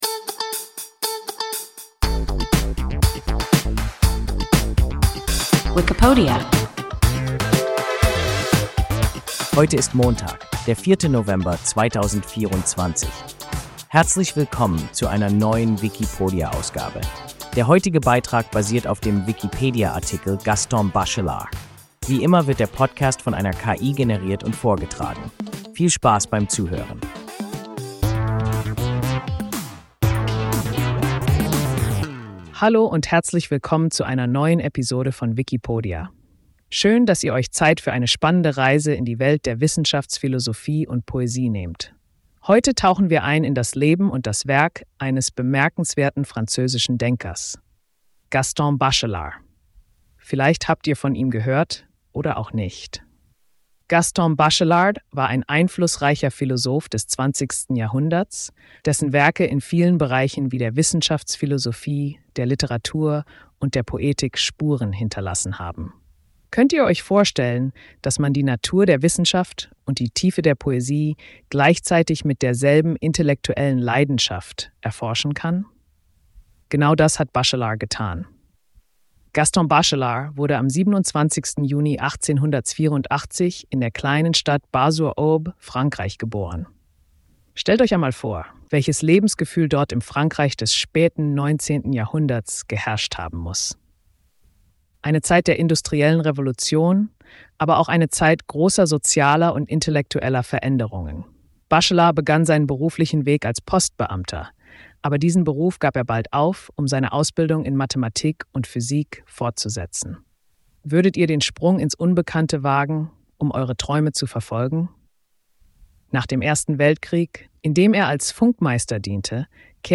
Gaston Bachelard – WIKIPODIA – ein KI Podcast